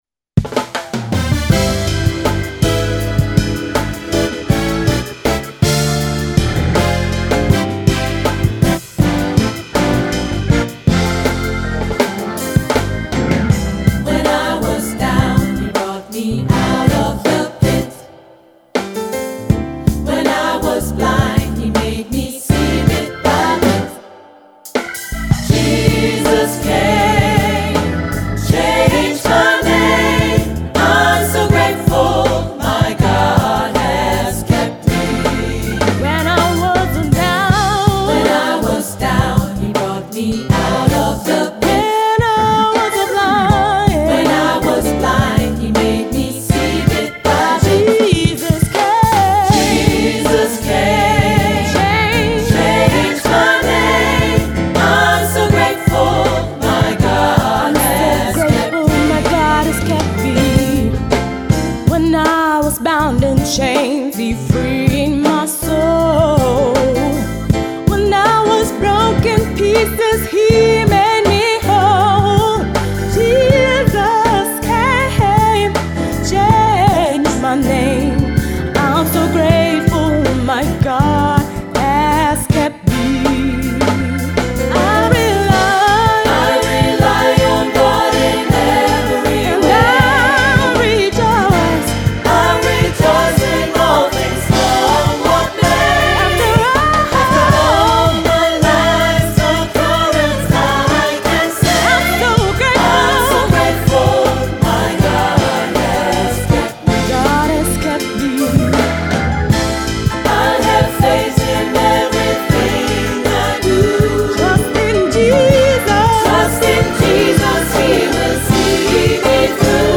• SATB, Solo + Piano